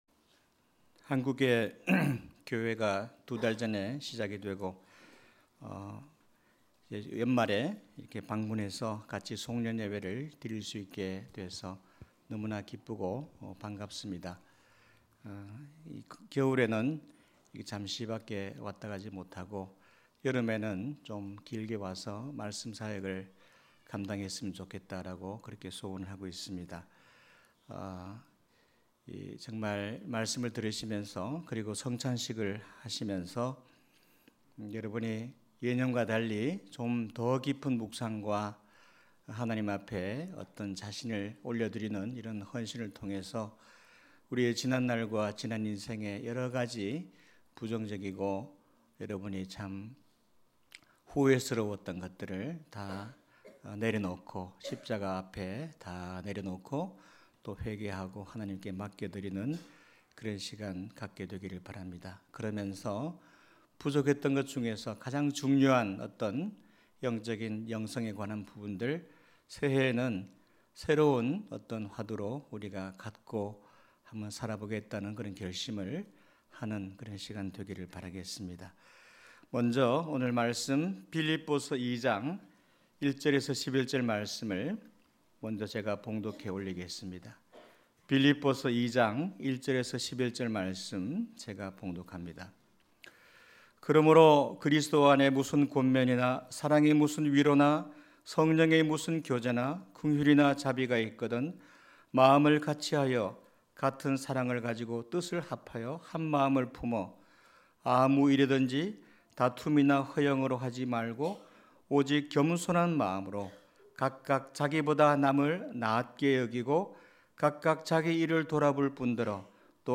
2011년 송구영신 예배